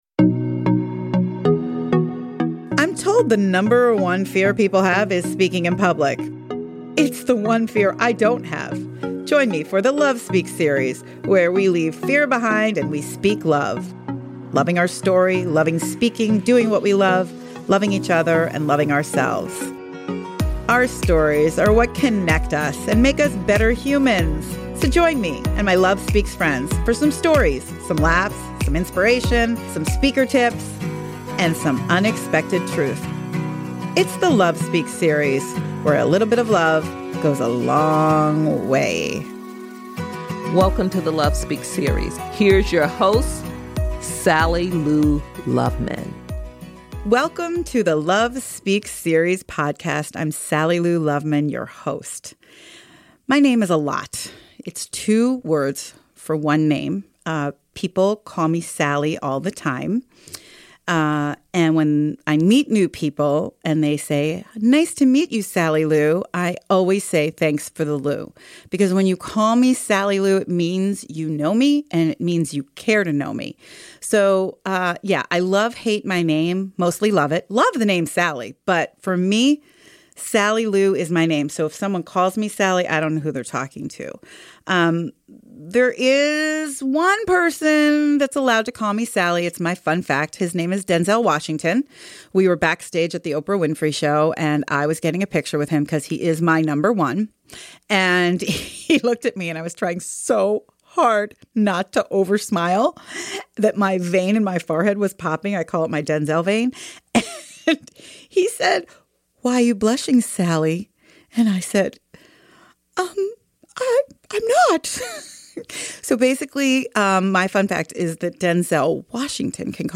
This episode is just me. the love speaks series has been in the making since I first recorded my voice on my reel to reel tape recorder in my basement at 6 years old. I’m so excited to share my stories with you and I can hardly wait for you to meet the heart centered professionals who will be joining me each week.